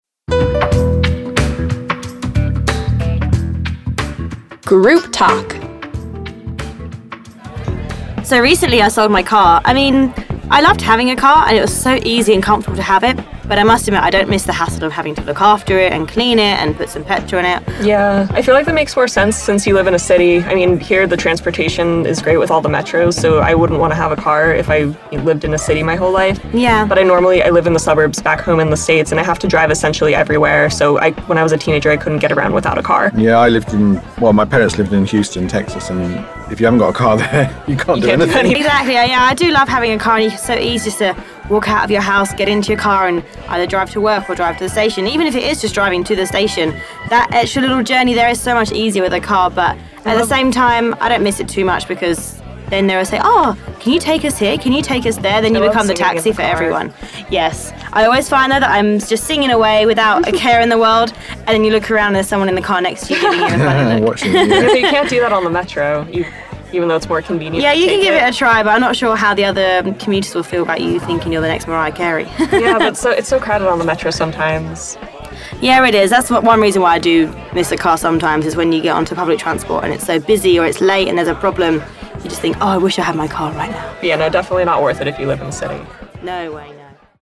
HE184_20_groupTalk.mp3